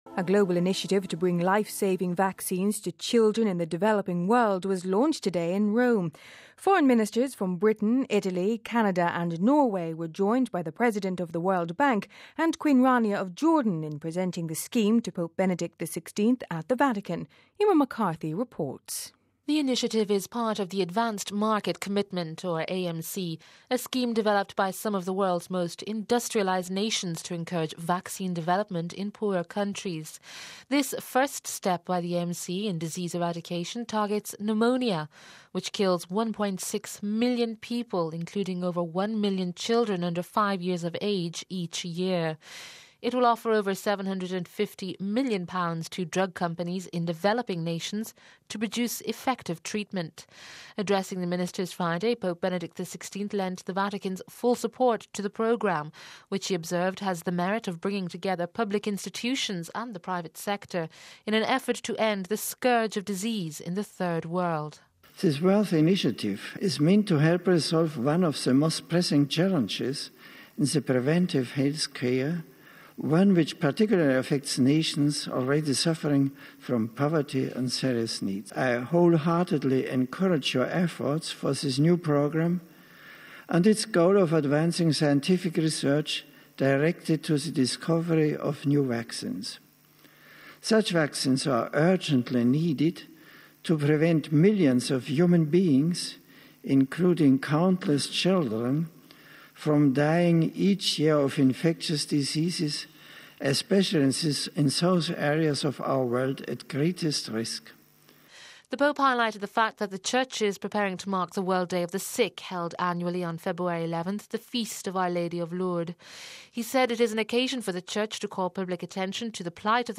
They were unveiling a new plan to support the creation of medicines for the world's poorest people. We have this report...